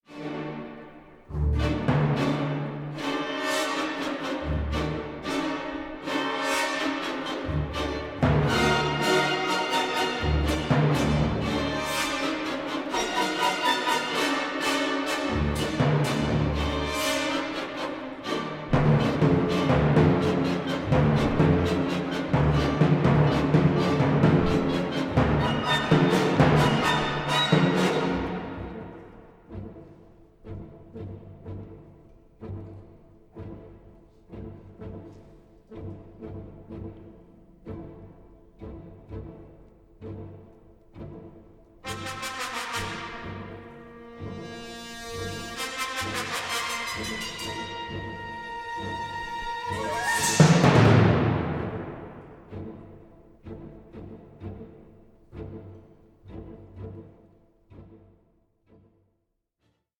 barbaric and savage music